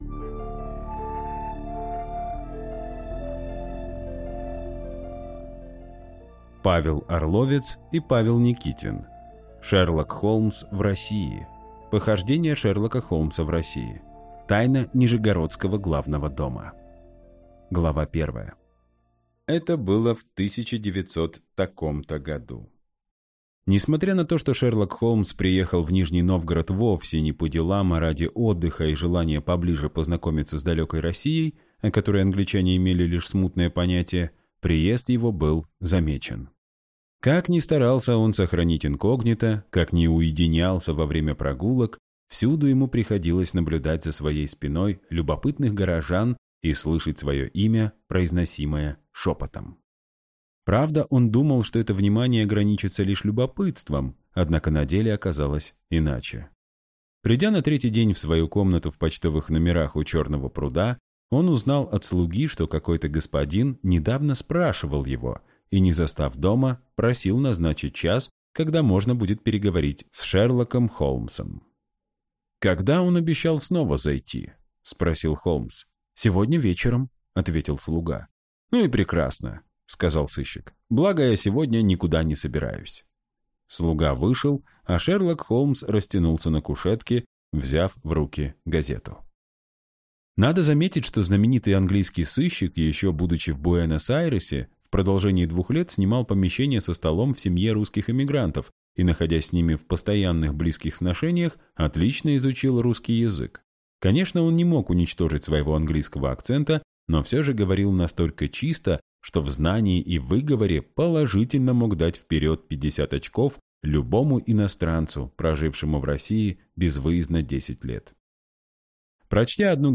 Аудиокнига Шерлок Холмс в России | Библиотека аудиокниг